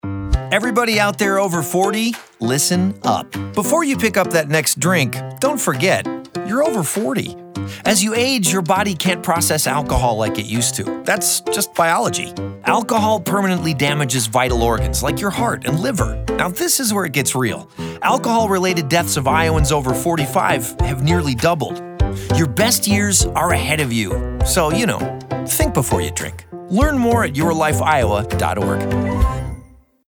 Radio spot :30 Radio | Think Before You Drink This campaign provides education and prevention resources to encourage low-risk alcohol consumption by older adults.